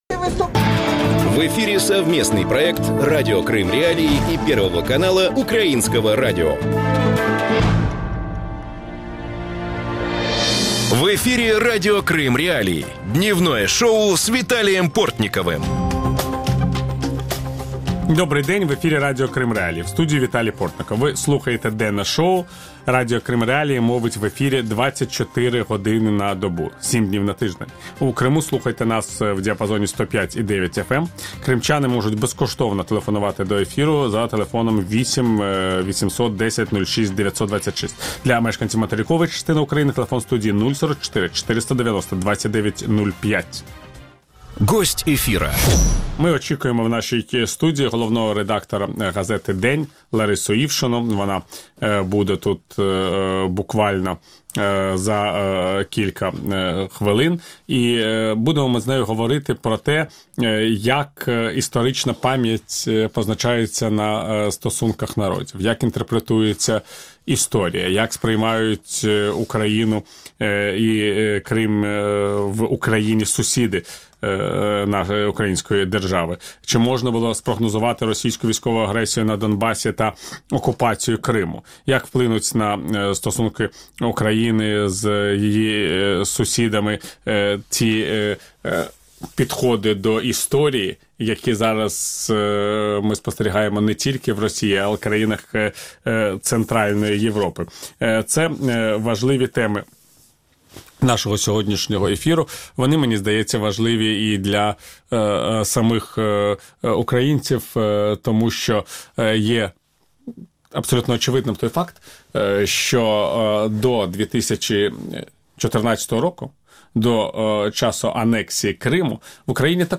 Как изменились отношения Украины с европейскими государствами? Об этом – в проекте «Дневное шоу» в эфире Радио Крым.Реалии с 12:10 до 12:40. Гость студии – Лариса Ившина, главный редактор газеты «День». Ведущий – Виталий Портников.